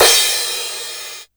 80s Digital Cymbal 01.wav